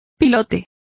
Complete with pronunciation of the translation of stilt.